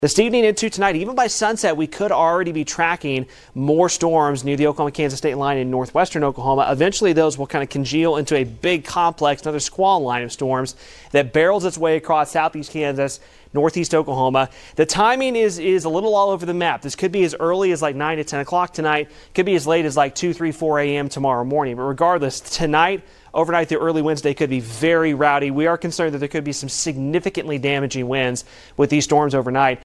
News on 6 Meteorologist